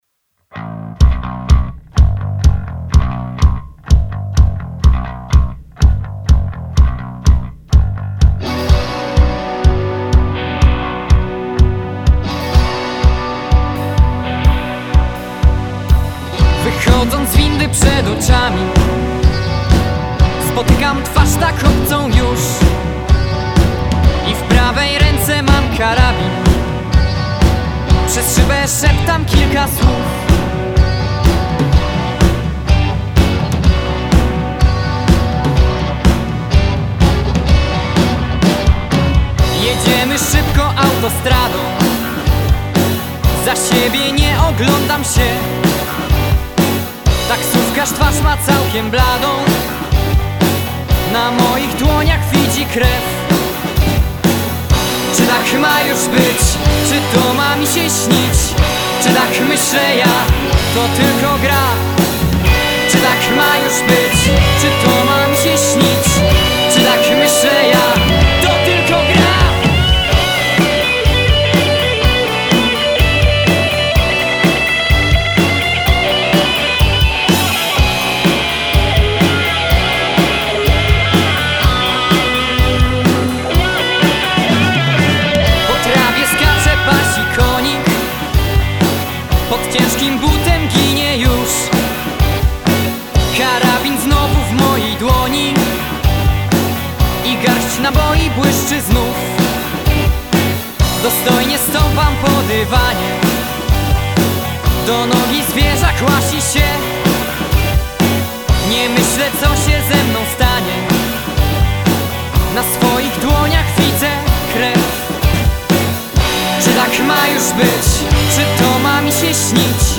Gatunek: Rock